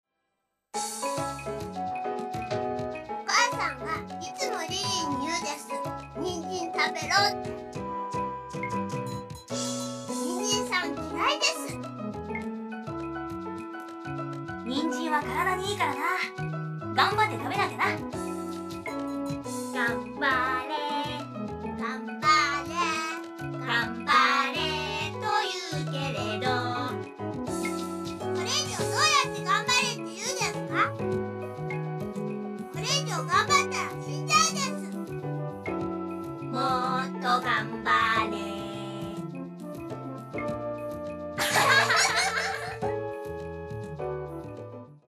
シナリオ終盤に発生したデュエット２曲目。
音量は意図的に小さめにしてあります。